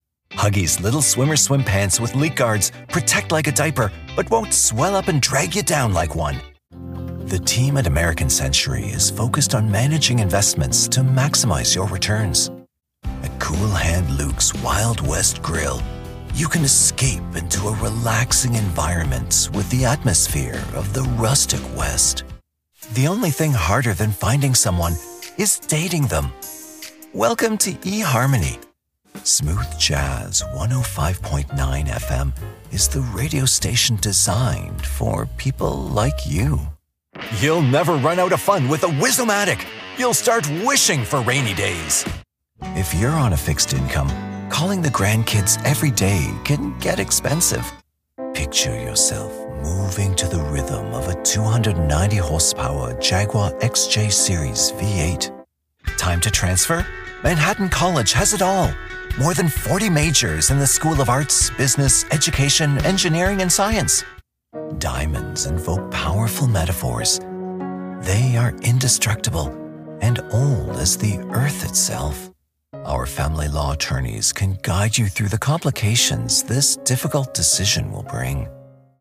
Commercial demo reel
English - Midwestern U.S. English
Middle Aged